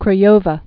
(krə-yōvə, krä-yôvä)